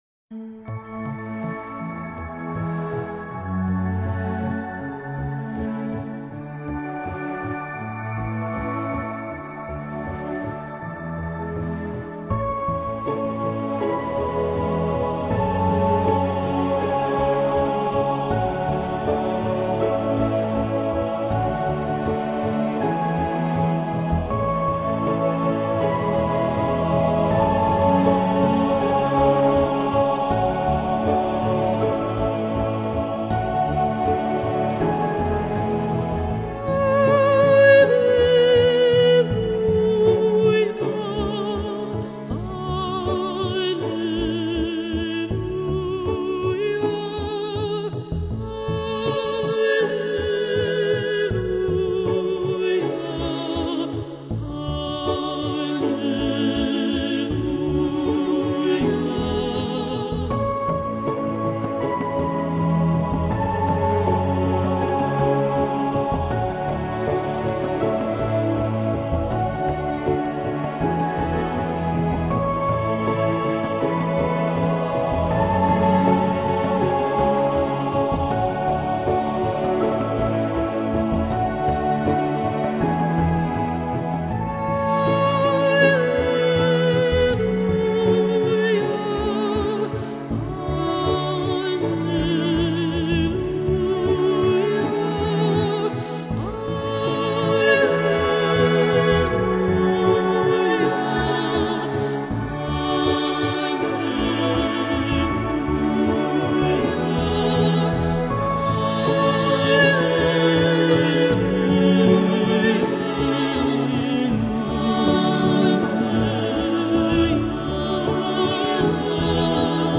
Classical Crossover, Vocal, Classical Arias